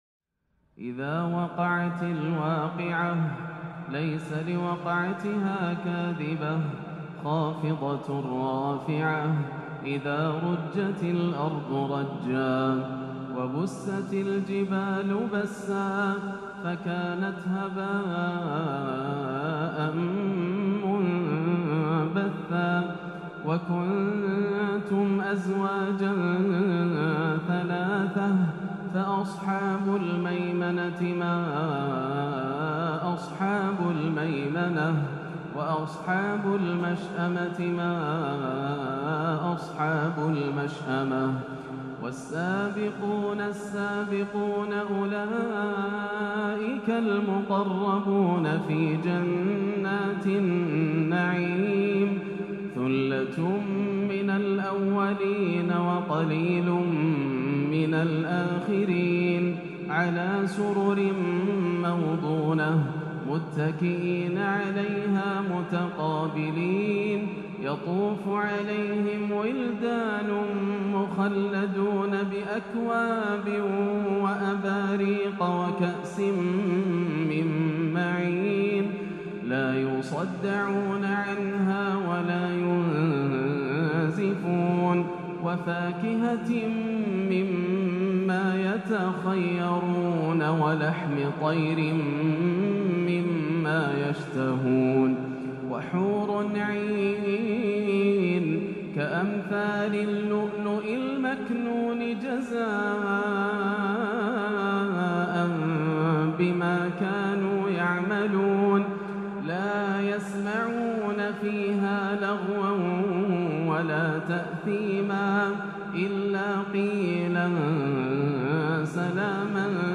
تلاوة بإنتقالات مميزة تلين القلوب وتأسر العقول من سورة الواقعة - الأحد 6-2-1438 > عام 1438 > الفروض - تلاوات ياسر الدوسري